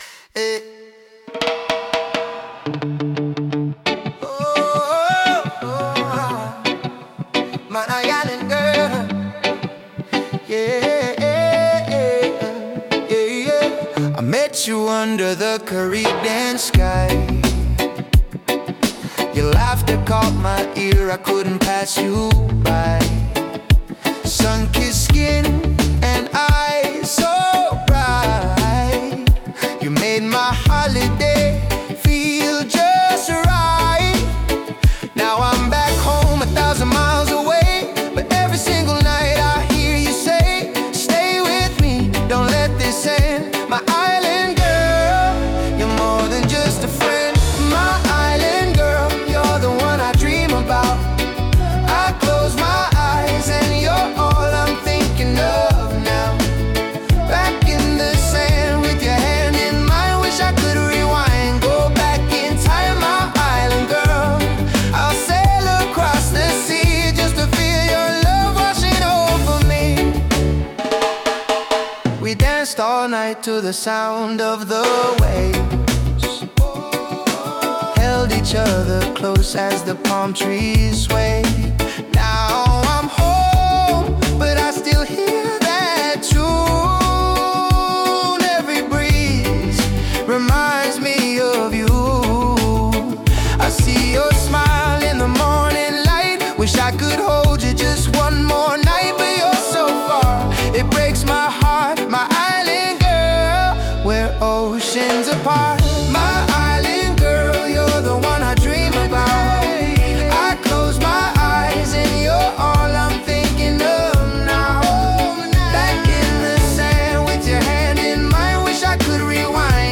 authentic English Ska/Reggae